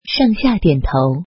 face_pitch_up.mp3